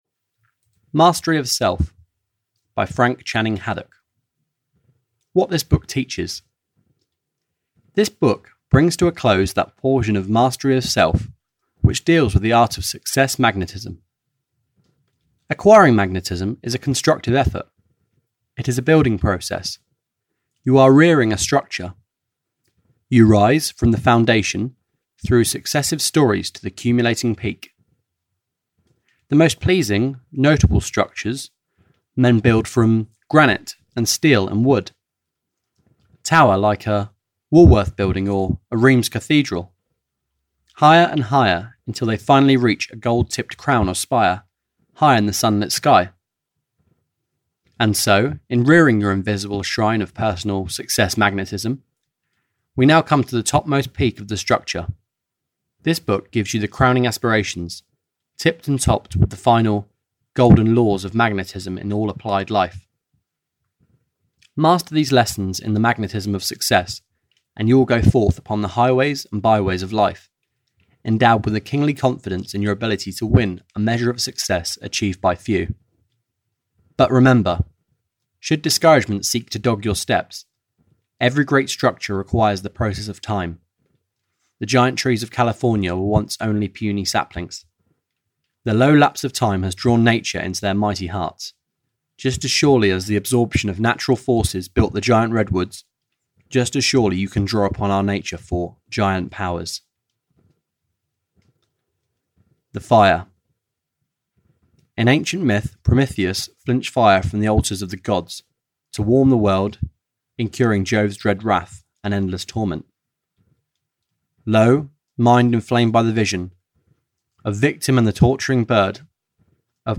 Mastery Of Self (EN) audiokniha
Ukázka z knihy